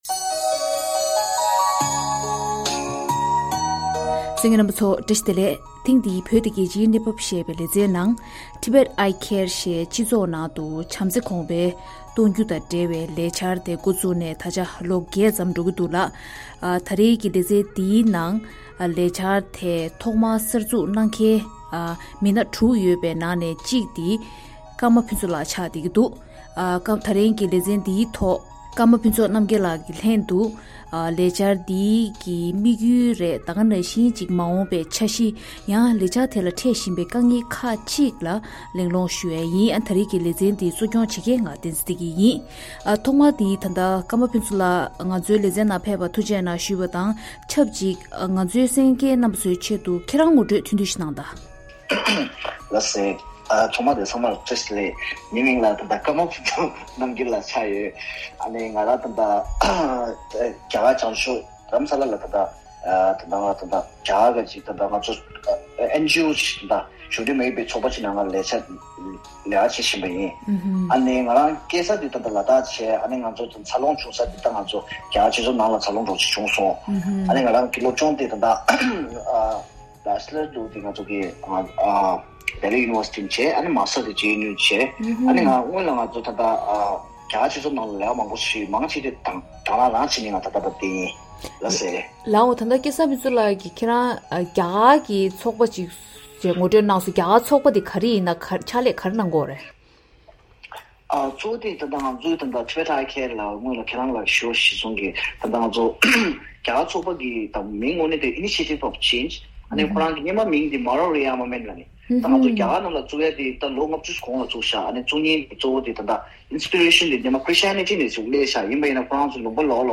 བཅར་འདྲི་གླེང་མོལ་གནང་བར་གསན་རོགས་གནང་།